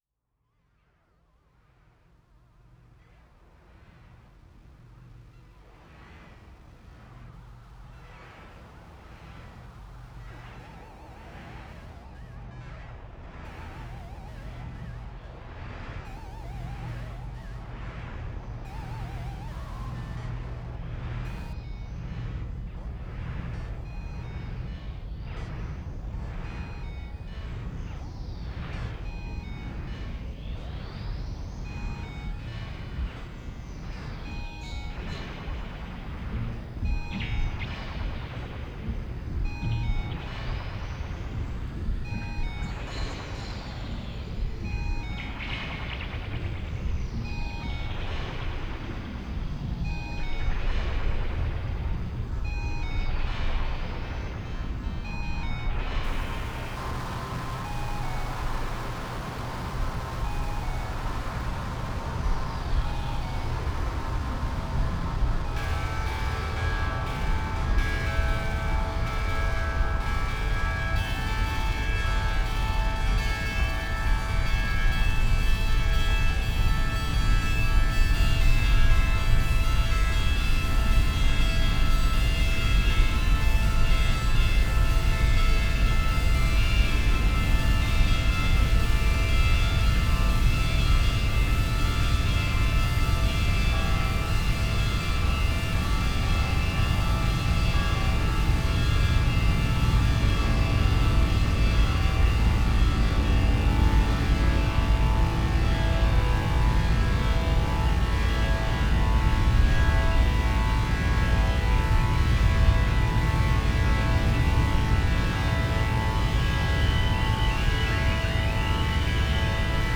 Part 133 @ the RCN CAVE 11/11/12